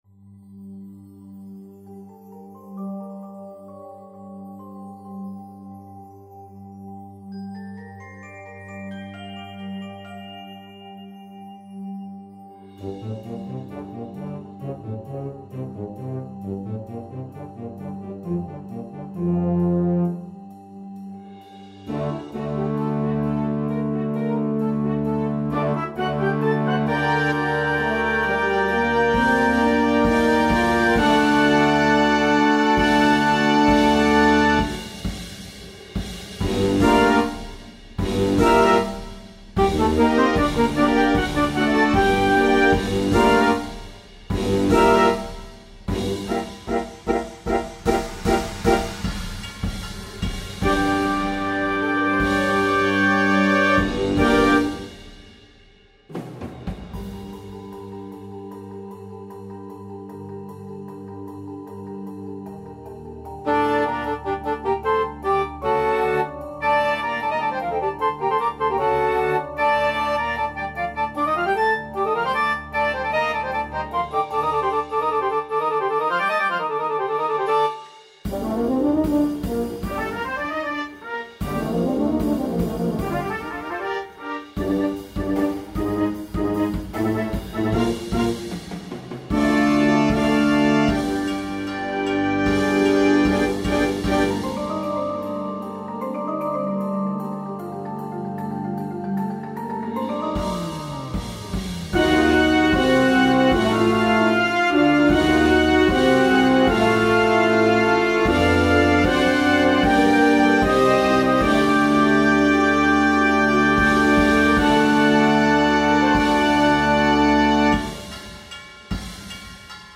is a thrilling, supernatural-themed marching band show
eerie, ethereal original soundscape
brings a high-energy vibe
dance-infused moment